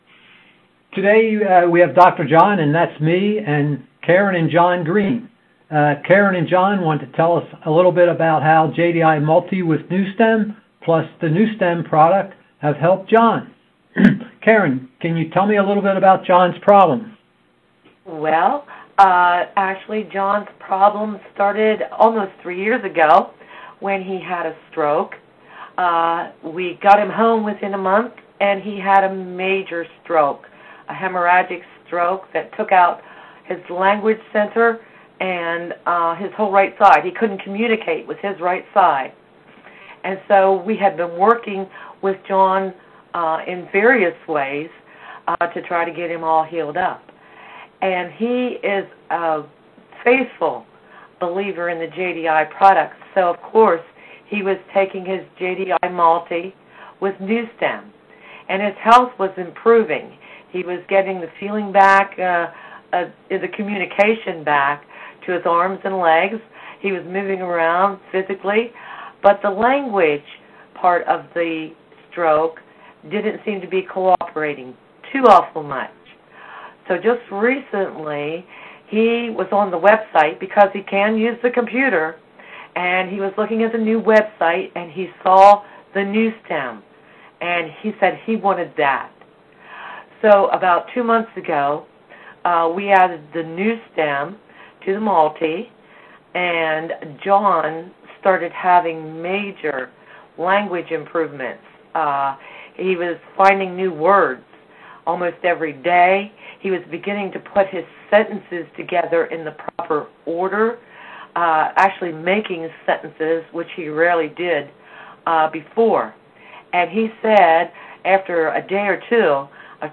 JDILife Audio Testimonials